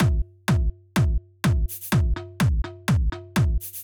Drumloop 125bpm 09-C.wav